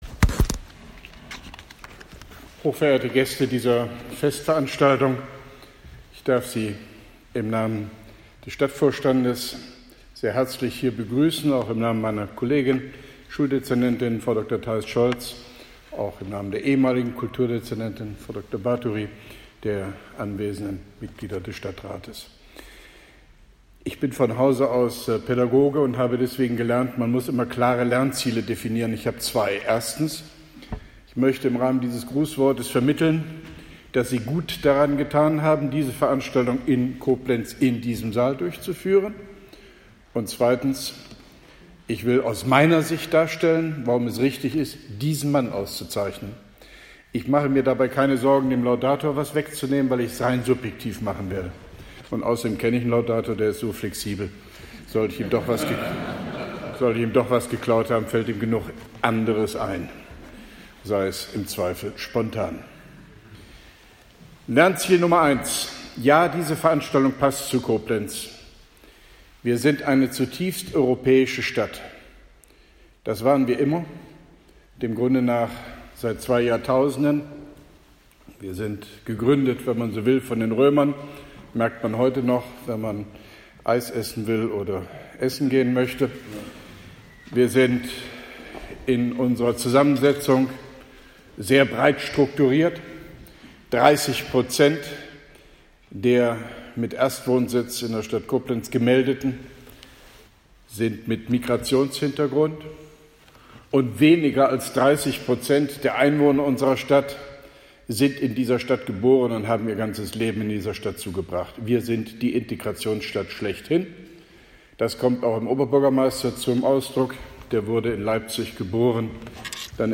Grußwort